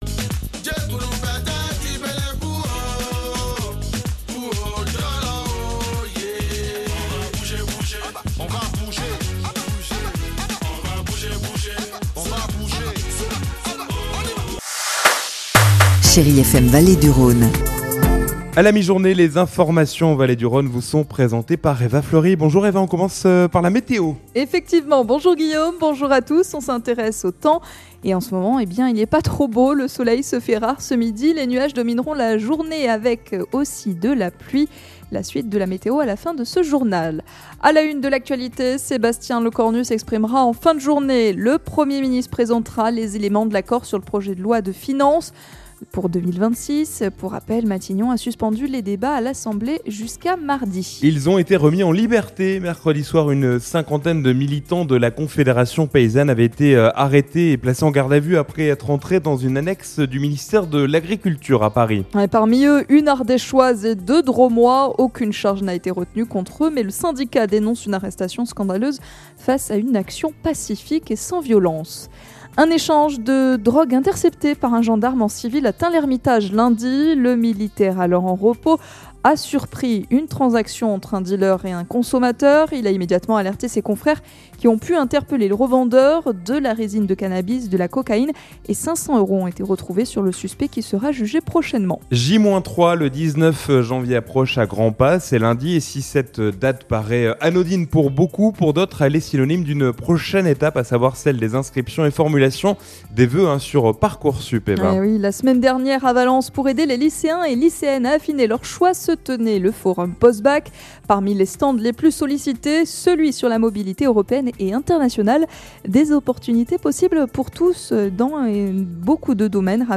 Vendredi 16 janvier : Le journal de 12h